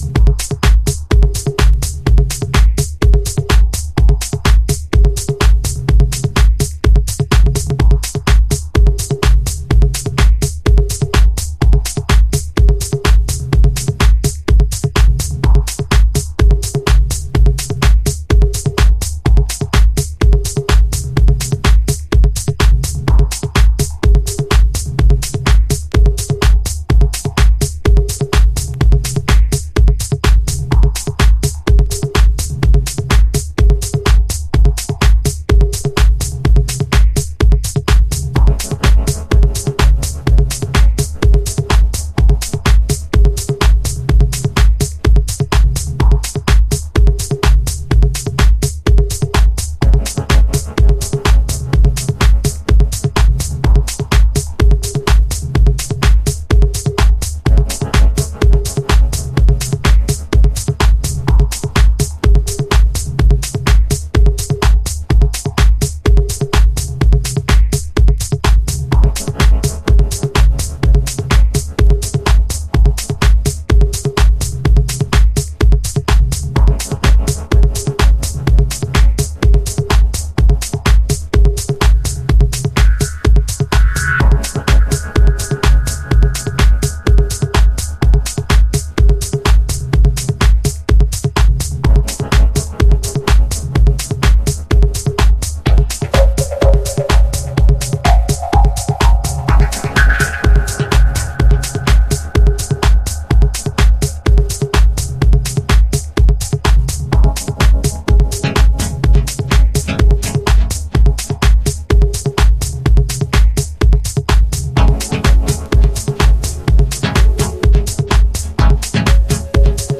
攻めのシンセウェーヴが心地良いダブテクノ。